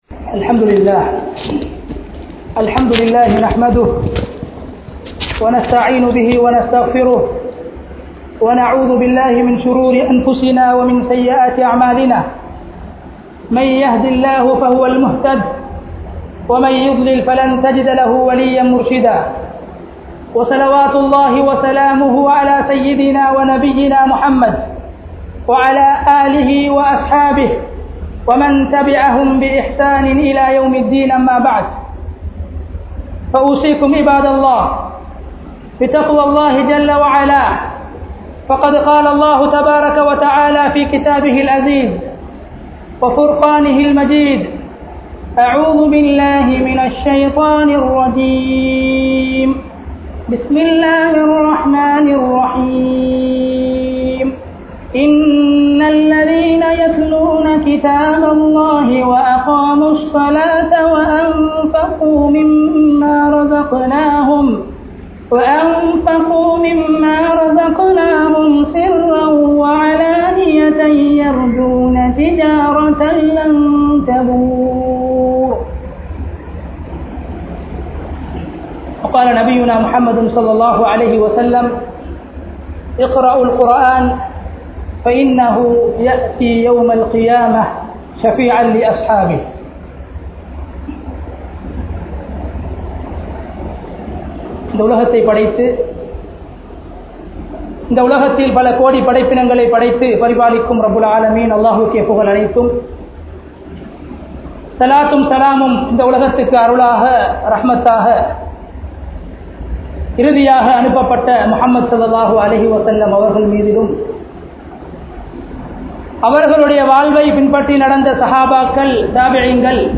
Al Quranin Mahimai (அல்குர்ஆனின் மகிமை) | Audio Bayans | All Ceylon Muslim Youth Community | Addalaichenai